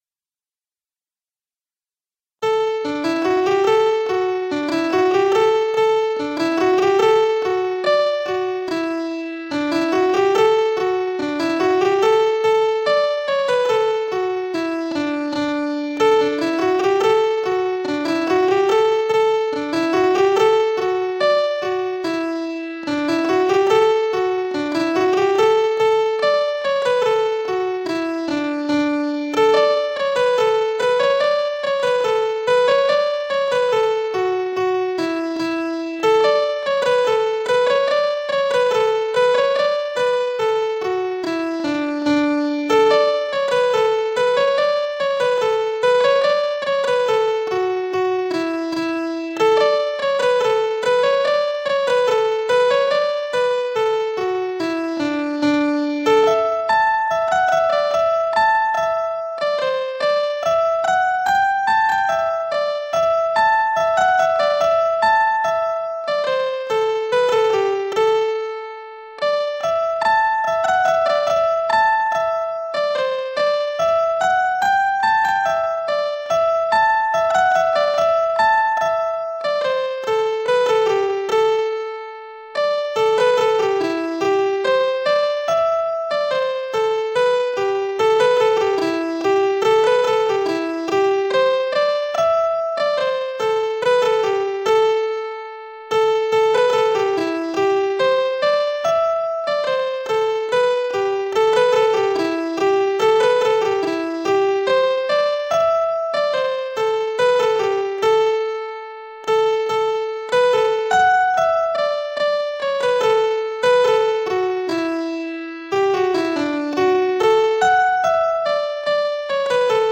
Three Aussie Reels Joe Yates Second Reel, Jack Cannys Reel and Ernie Wells Riverboat (reel) . Resources: The Score of the music (PDF) Listen to the tune on piano, no chords, 116 beats per minute(MP3) Listen to the tune slowed for learning to 60 beats per minute (MP3) List by Title List by Composer List by State